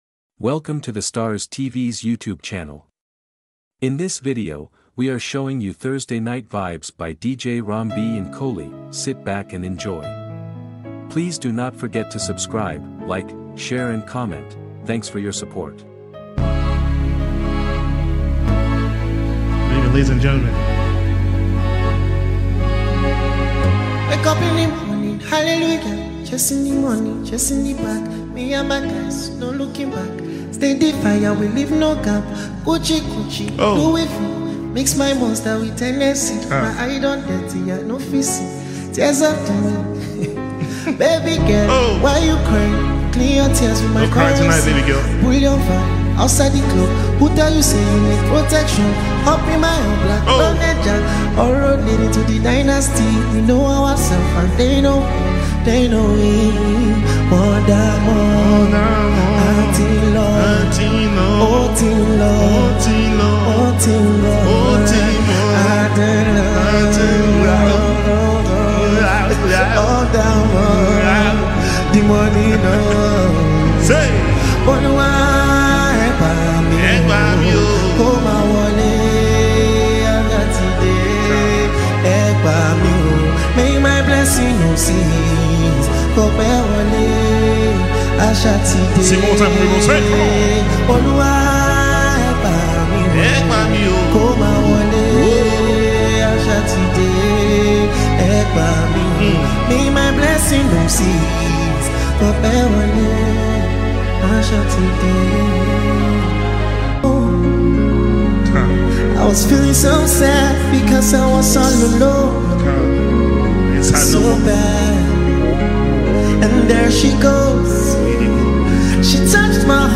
Dj Mix